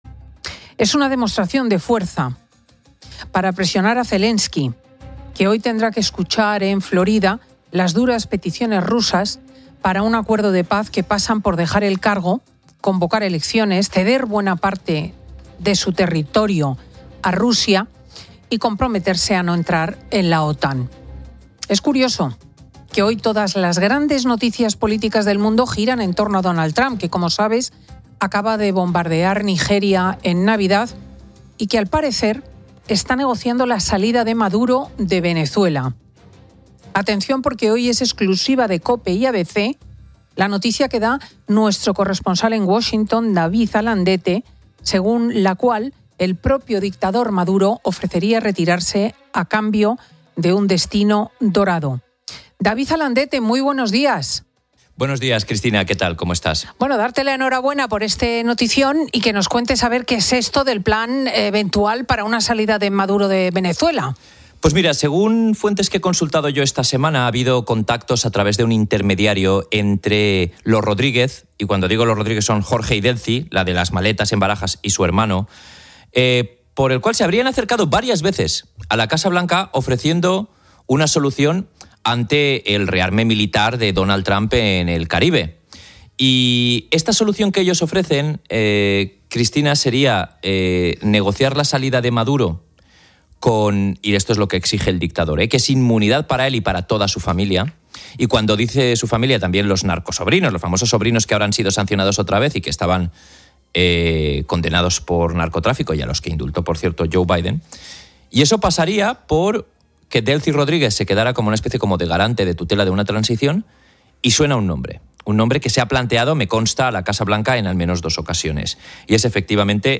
Cristina López Schlichting descubre con David Alandete el plan de salida de Maduro de Venezuela